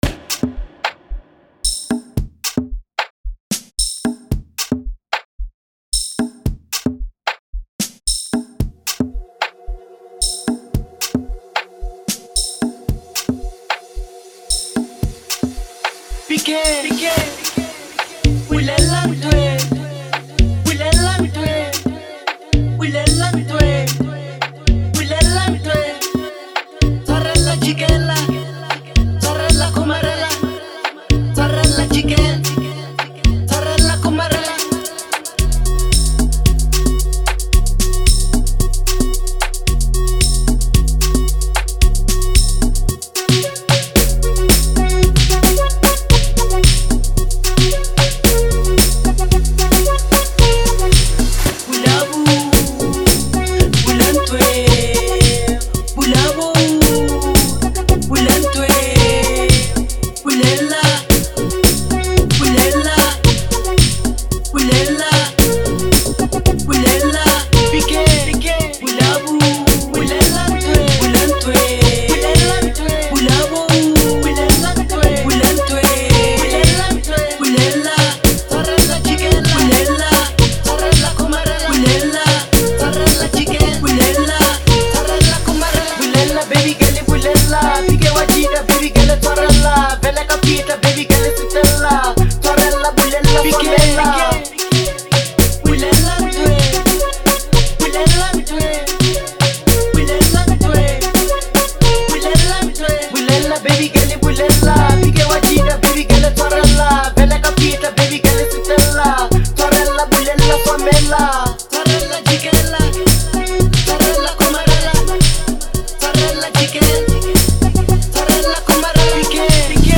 07:00 Genre : Amapiano Size